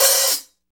HAT REAL H0L.wav